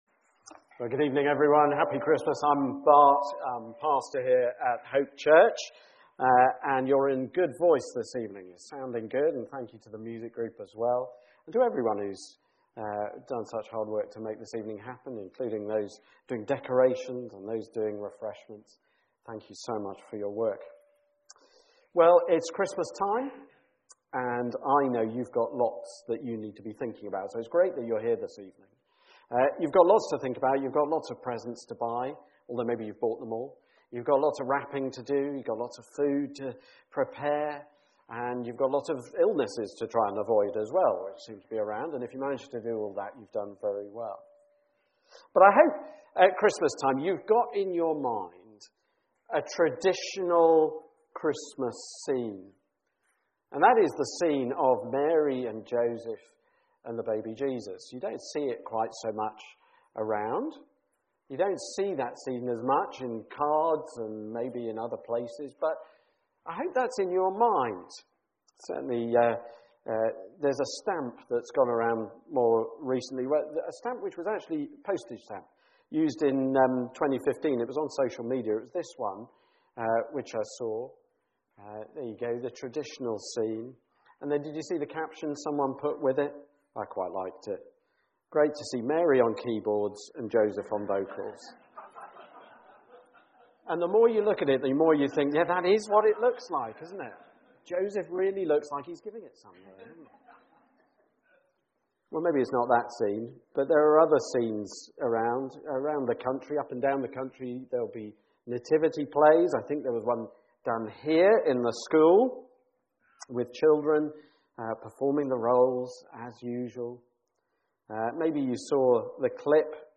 Sermon Scripture 18 Now the birth of Jesus Christ took place in this way.